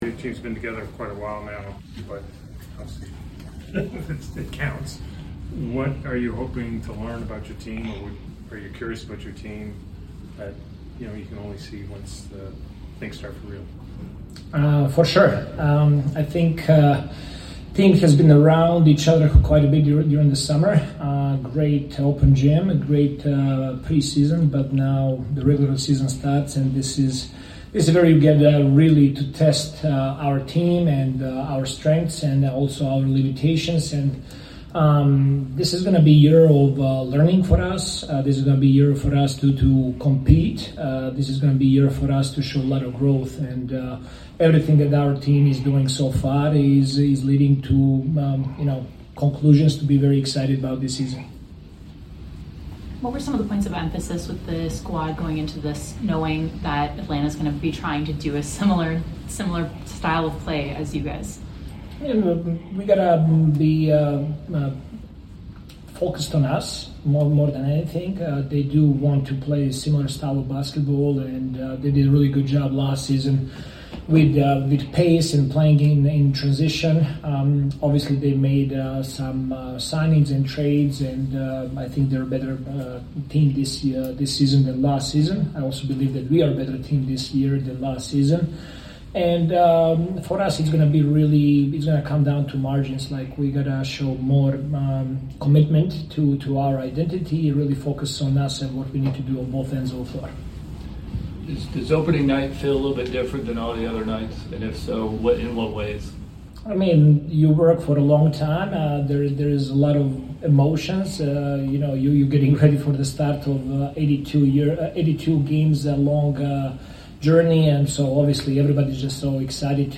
Toronto Raptors Coach Darko Rajakovic Pregame Interview before taking on the Atlanta Hawks at State Farm Arena.